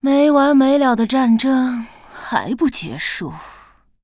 文件 文件历史 文件用途 全域文件用途 Cyrus_amb_03.ogg （Ogg Vorbis声音文件，长度4.0秒，101 kbps，文件大小：50 KB） 源地址:游戏语音 文件历史 点击某个日期/时间查看对应时刻的文件。